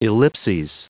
Prononciation du mot ellipses en anglais (fichier audio)
Prononciation du mot : ellipses